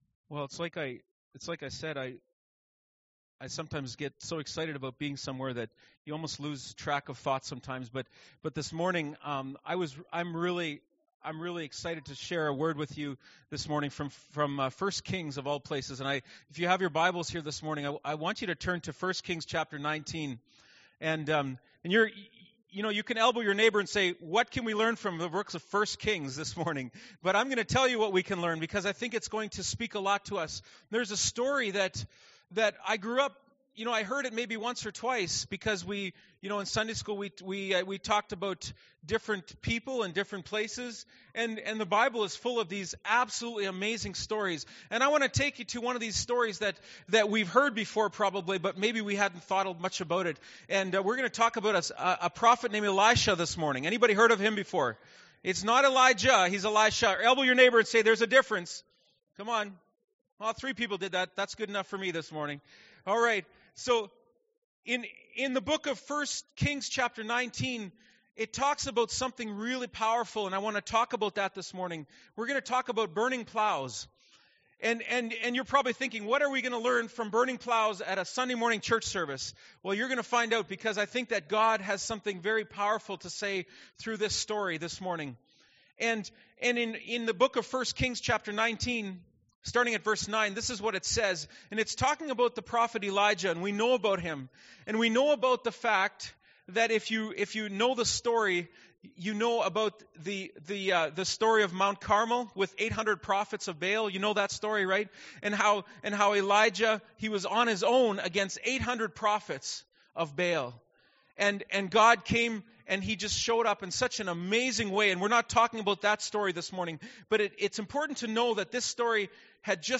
March 29, 2015 – Sermon